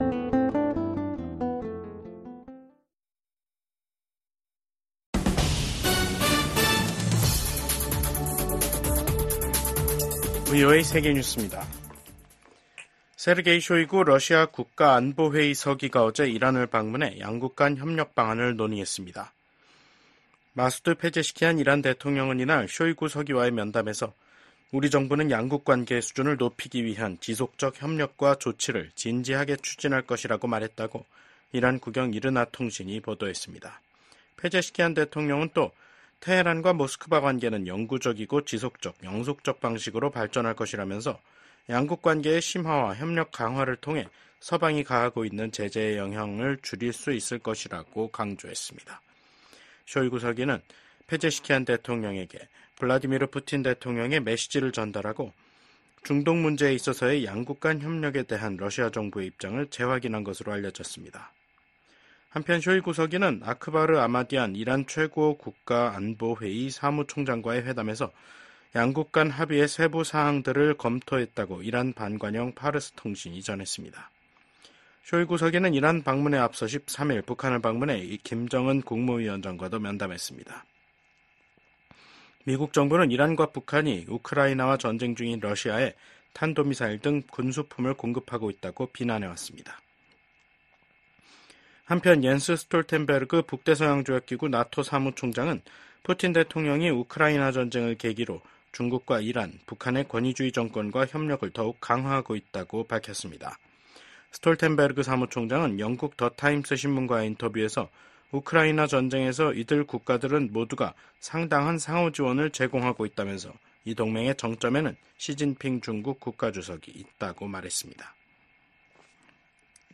VOA 한국어 간판 뉴스 프로그램 '뉴스 투데이', 2024년 9월 18일 2부 방송입니다. 북한이 한반도 시각 18일 탄도미사일 여러 발을 발사했습니다. 한미연합사령관 지명자가 북한의 핵과 미사일 역량 진전을 최대 도전 과제로 지목했습니다.